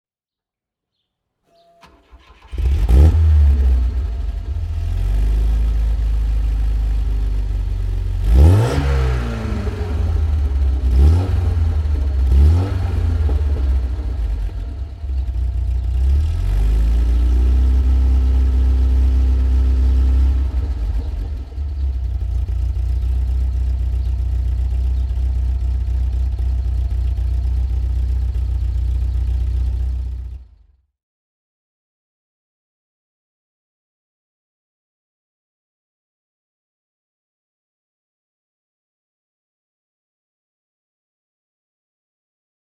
Volvo P1800 E (1970) - Starting and idling